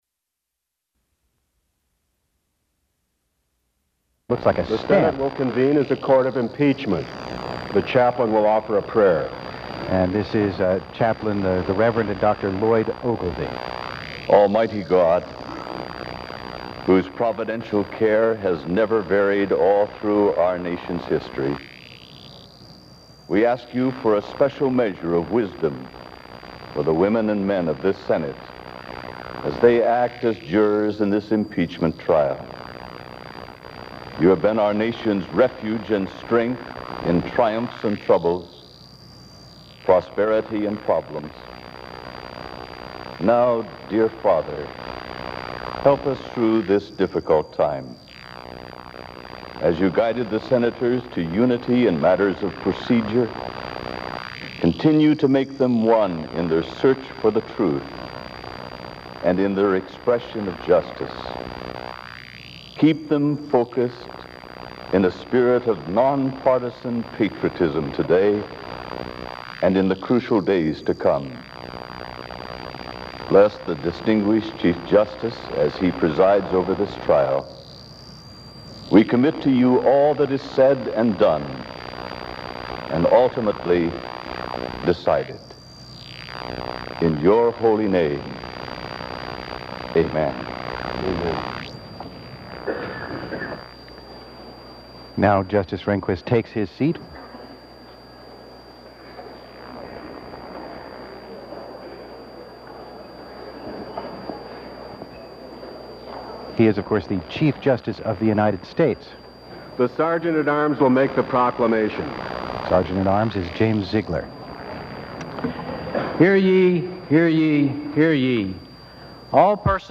James Sensenbrenner (R-WS) testifies on impeaching President Clinton
Other speakers include Senate Chaplain Lloyd Ogilvie, Sergeant-at- Arms James Ziglar, Chief Justice William Rehnquist, and Rep. Henry Hyde (R-IL).
Committee on the Judiciary Subjects Clinton, Bill, 1946- Impeachments Trials (Impeachment) United States Material Type Sound recordings Language English Extent 00:73:00 Venue Note Broadcast on C-Span 2, Jan. 14, 1999.